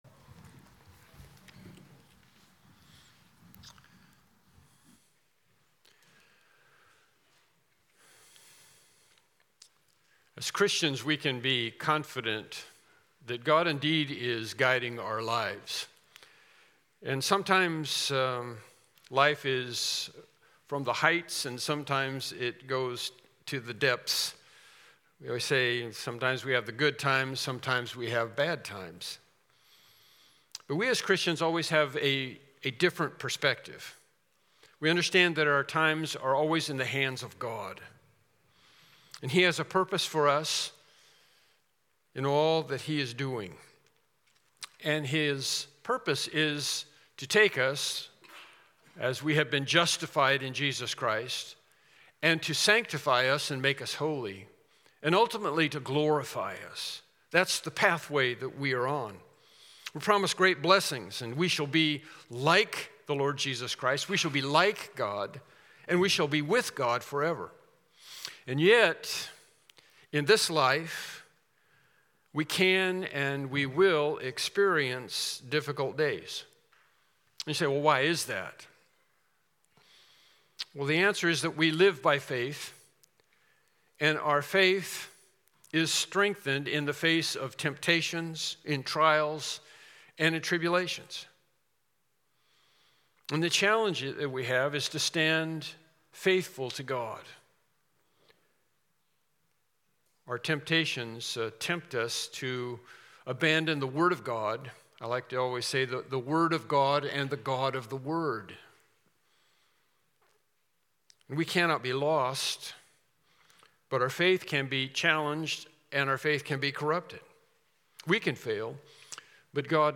Mark 1:12-13 Service Type: Morning Worship Service « The Anointing of the Messiah Lesson 8